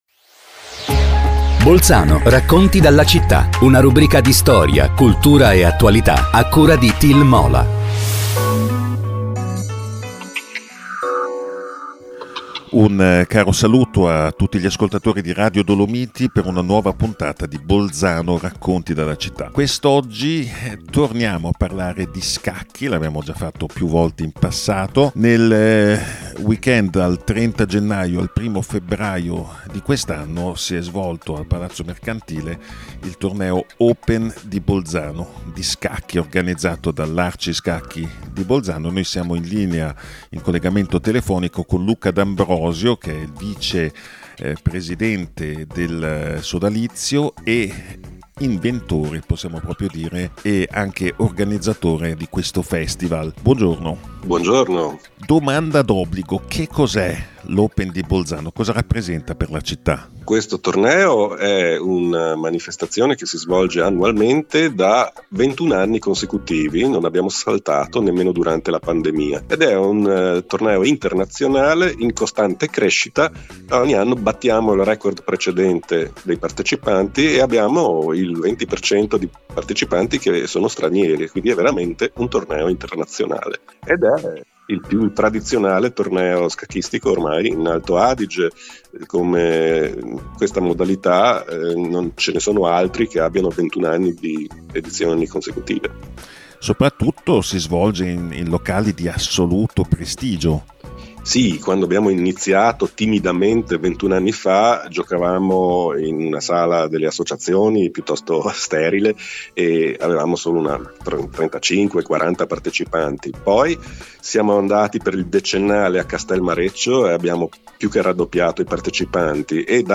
Il torneo Open di scacchi – intervista